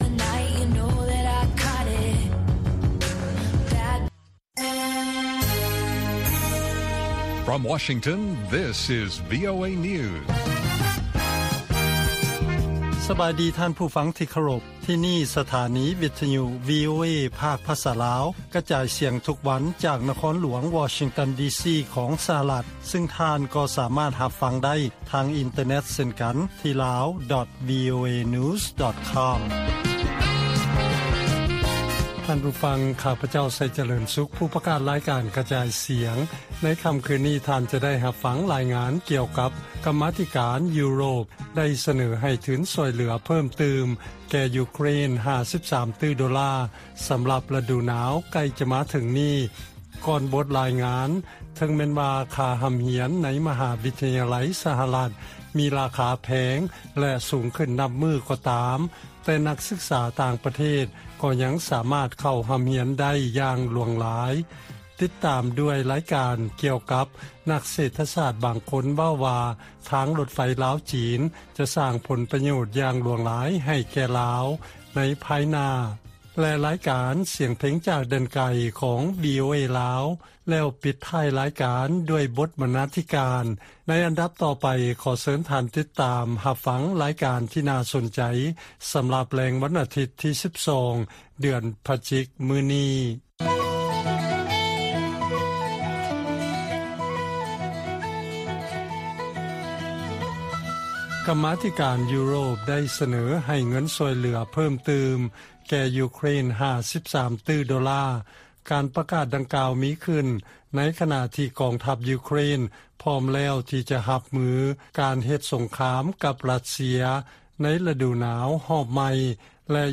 ລາຍການກະຈາຍສຽງຂອງວີໂອເອລາວ: ທາງລົດໄຟຄວາມໄວສູງ ມີຄວາມສຳຄັນສຳລັບລາວ ແຕ່ກໍເປັນໜີ້ຫຼາຍຂຶ້ນ